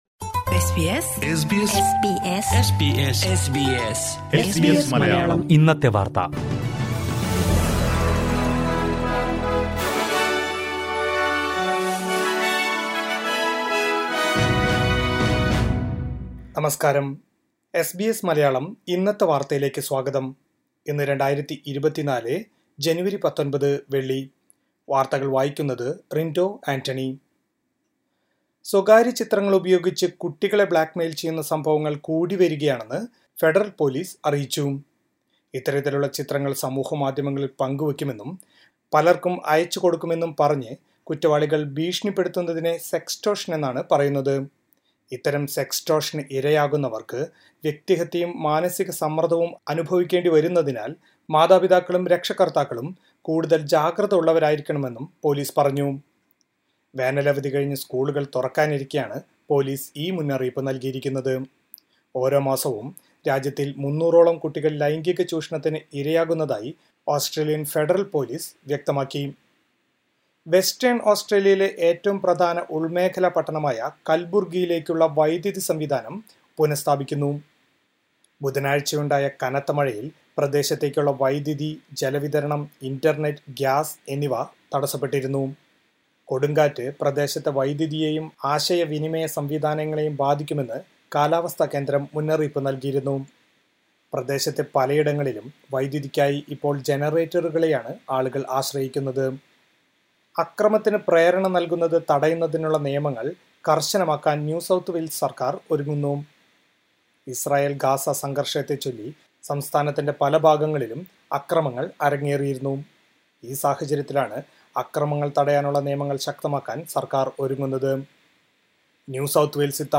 2024 ജനുവരി 19ലെ ഓസ്‌ട്രേലിയയിലെ ഏറ്റവും പ്രധാനപ്പെട്ട വാര്‍ത്തകള്‍ കേള്‍ക്കാം.